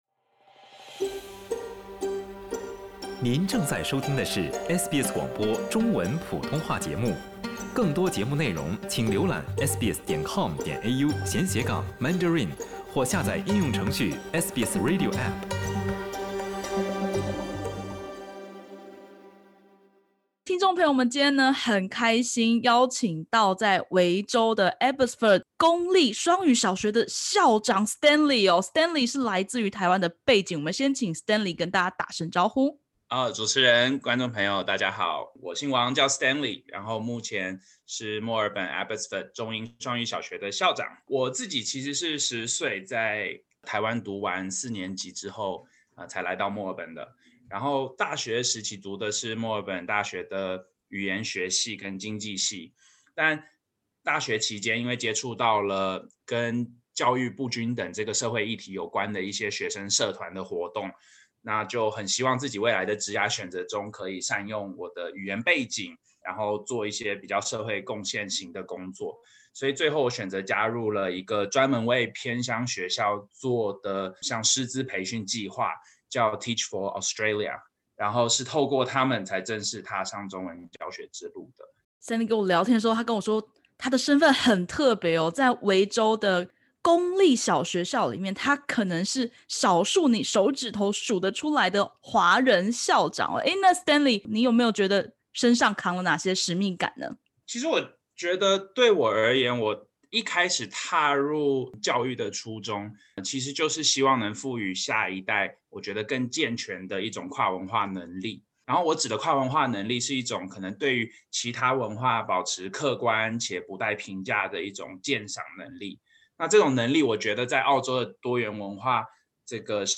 点击首图收听完整采访音频。